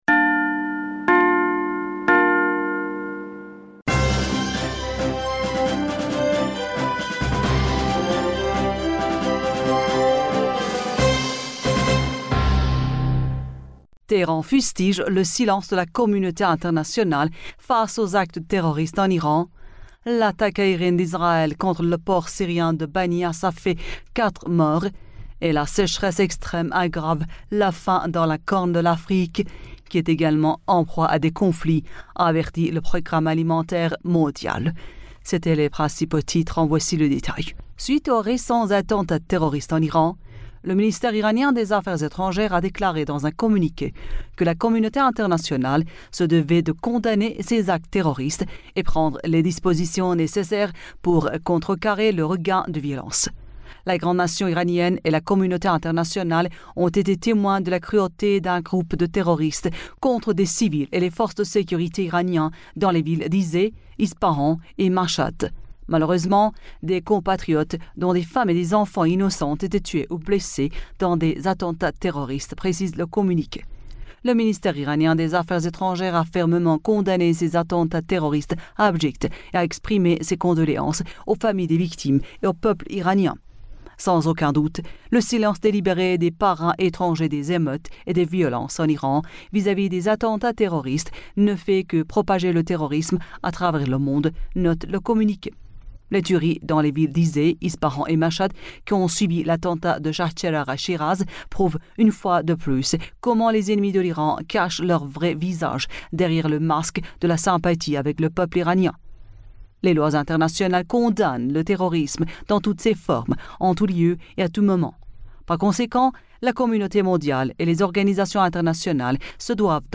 Bulletin d'information du 19 Novembre